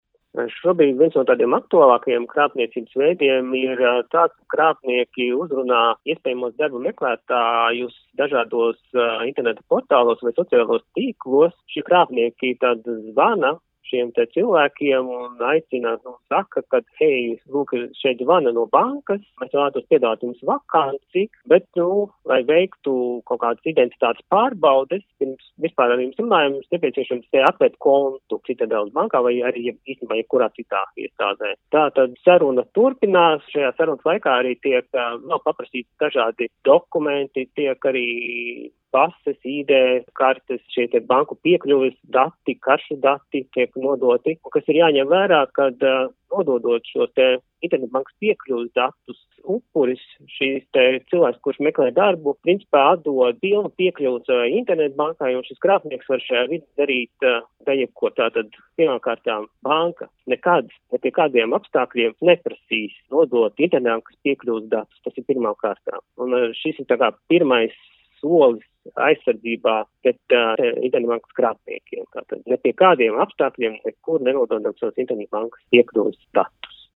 RADIO SKONTO Ziņās par jaunu krāpniecības veidu